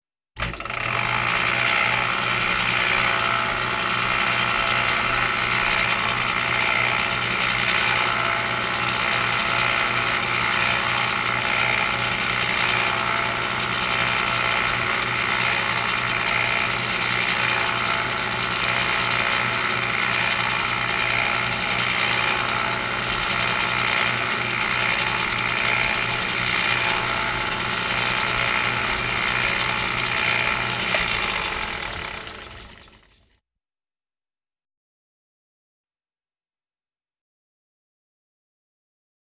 Milling Machine Start And Stop Sound Effect
Download a high-quality milling machine start and stop sound effect.
milling-machine-start-and-stop.wav